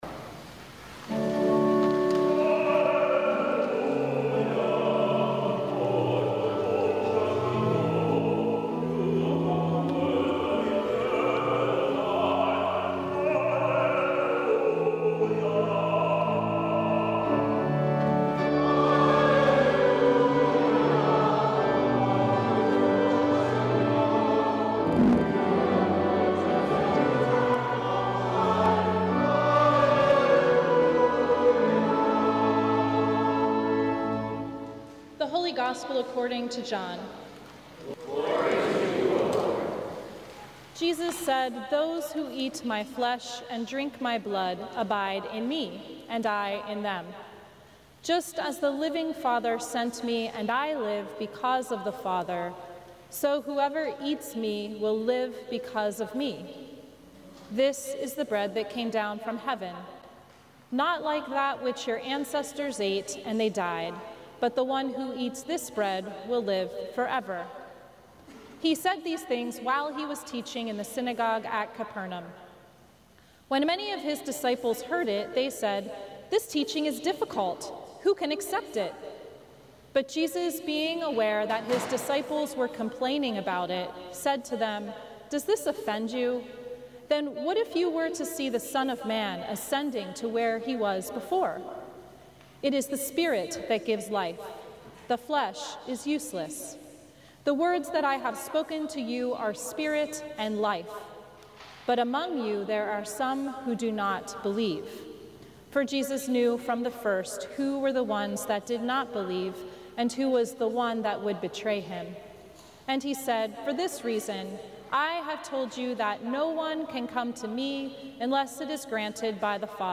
Sermon from the Fourteenth Sunday After Pentecost